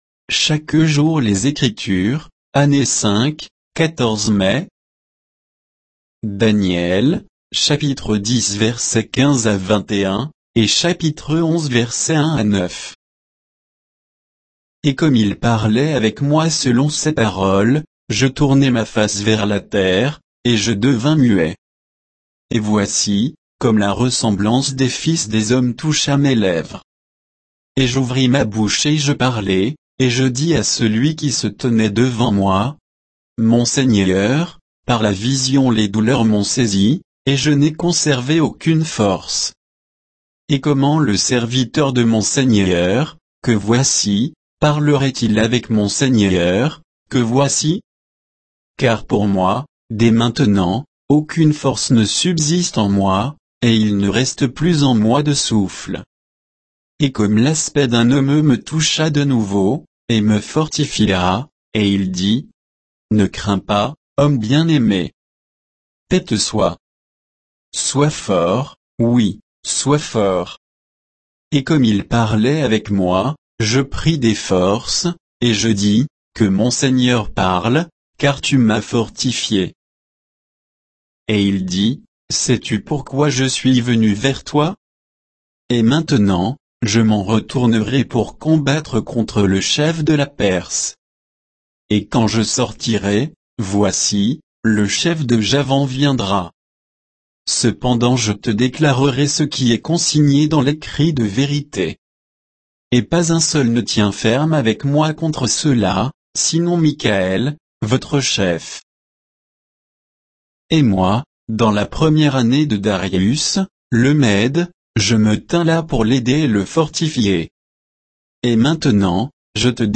Méditation quoditienne de Chaque jour les Écritures sur Daniel 10